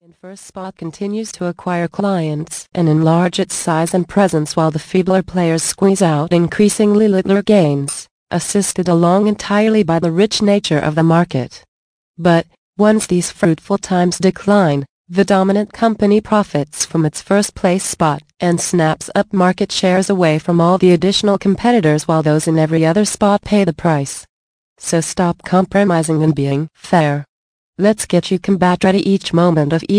Alpha Dog mp3 audio book - Resell Rights Included